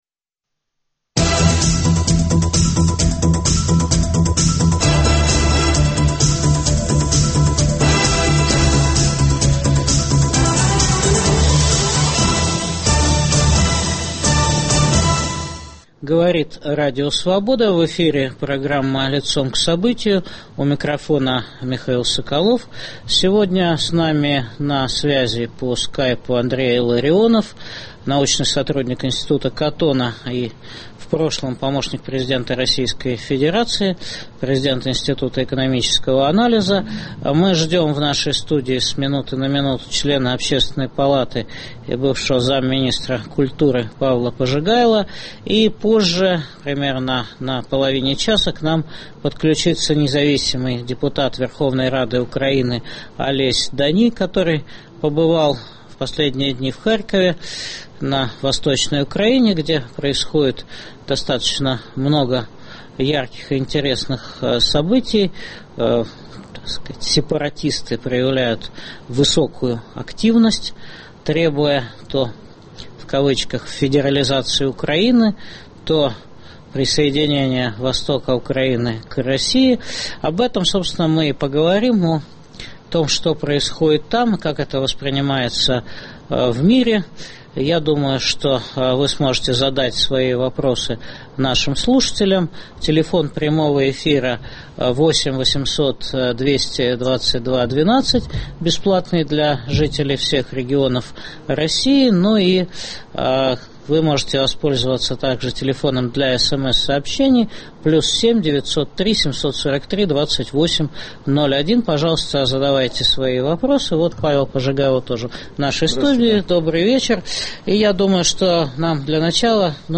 О дестабилизации Украины дискутируют политики Андрей Илларионов, Павел Пожигайло, Олесь Доний.